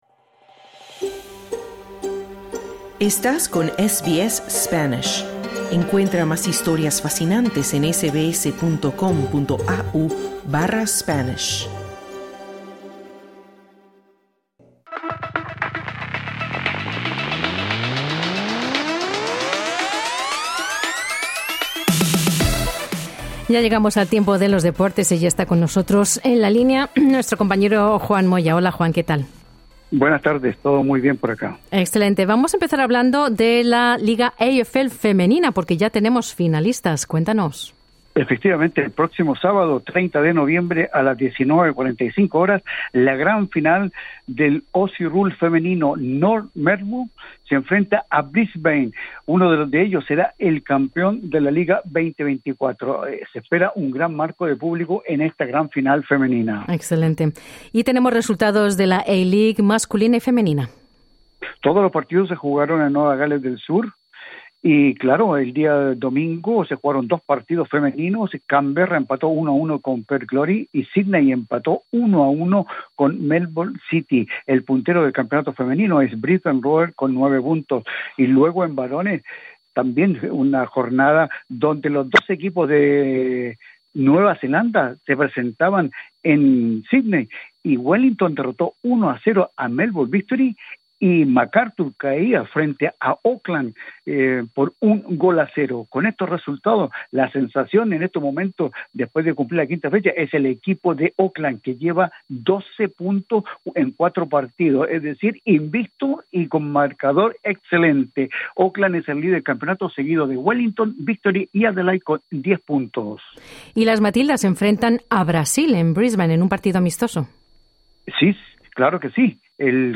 Italia se coronó campeona de la Copa Davis de tenis luego de derrotar a Países Bajos. Max Verstappen es nuevamente campeón de la Fórmula Uno. Escucha el informe deportivo en el podcast localizado en la parte superior de esta página.